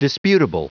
Prononciation du mot disputable en anglais (fichier audio)
Prononciation du mot : disputable